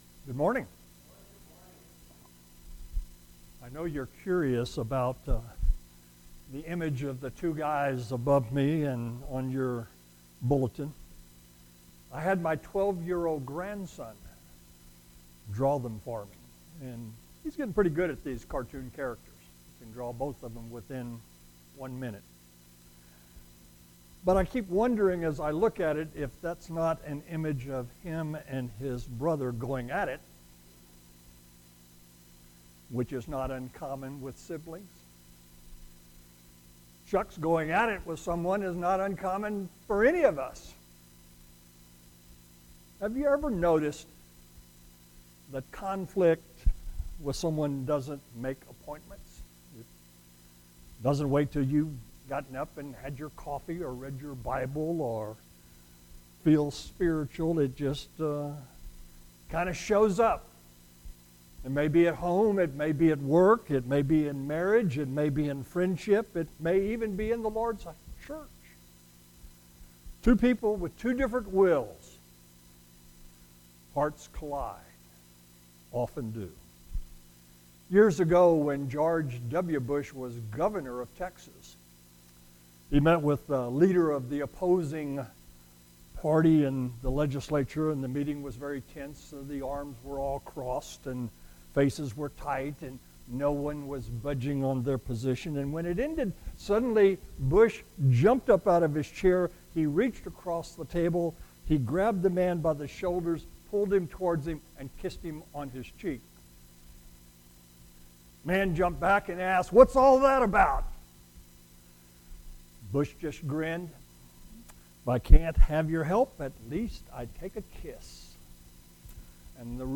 Audio Sermons - Babcock Road Christian Church